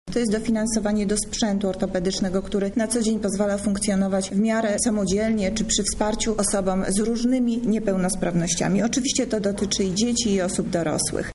– mówi Monika Lipińska, zastępca prezydenta Lublina